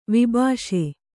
♪ vibhāṣe